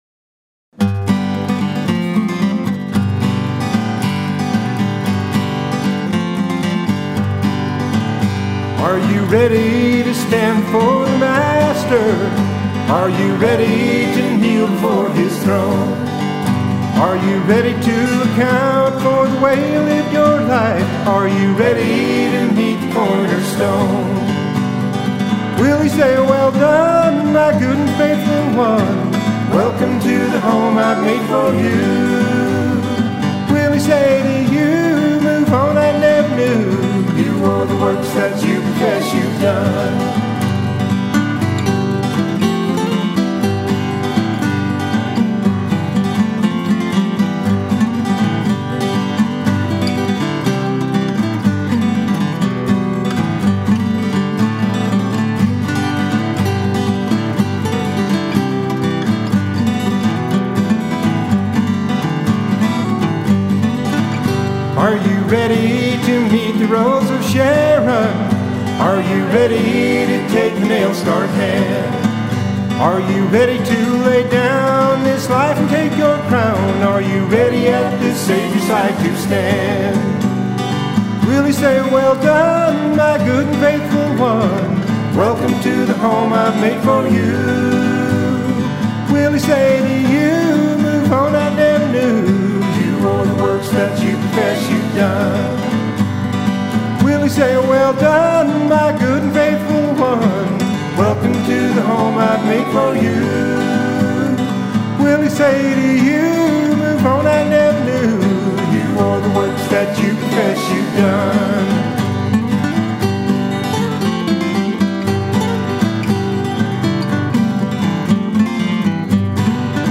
Vocals/Guitar/Autoharp/Keyboard
Mandolin/Bass/Guitar/Vocals
Banjo/Dobro/Clarinet/Vocals